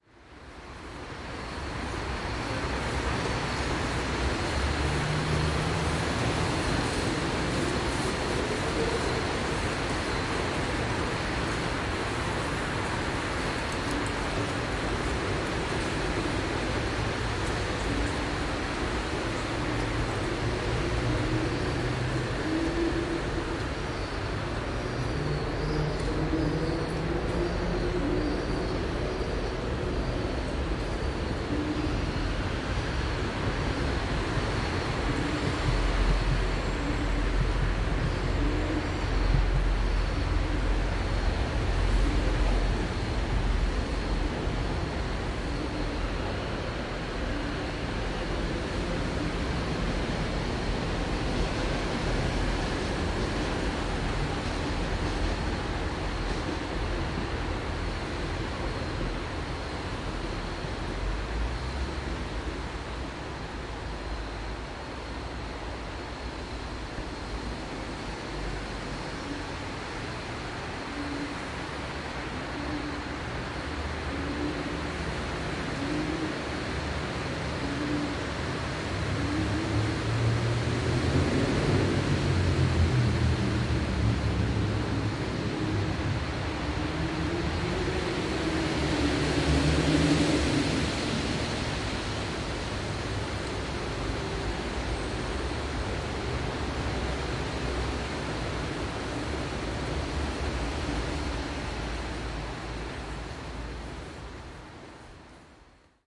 描述：在高峰时间在公路桥梁下进行的现场录音。靠近运河和火车轨道。
Tag: 交通 城市 市容